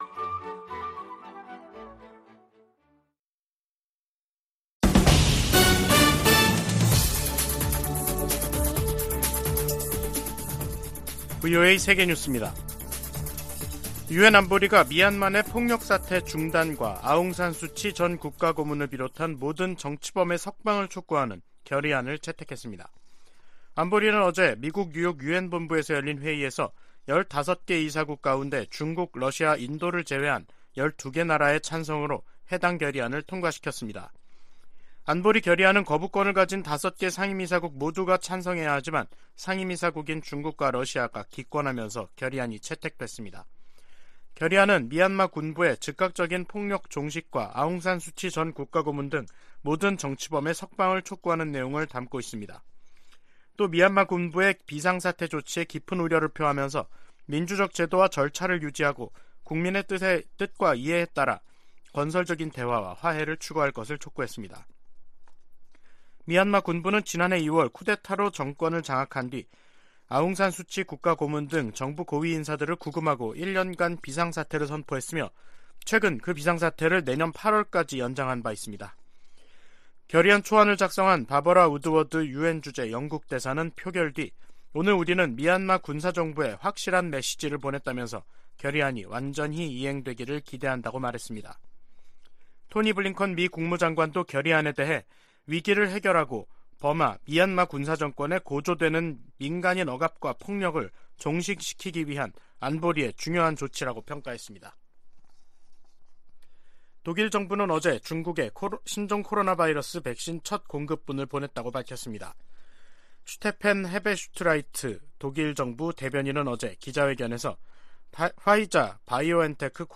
VOA 한국어 간판 뉴스 프로그램 '뉴스 투데이', 2022년 12월 22일 2부 방송입니다. 미국 의회는 2023회계연도 일괄 지출안에서 북한 관련 지출은 인권 증진과 대북 방송 활동에만 국한하도록 규정했습니다. 미국은 내년 아시아태평양경제협력체(APEC) 의장국으로서 경제적 지도력을 보여주고 역내의 경제 회복력 강화를 도모할 것이라고 밝혔습니다.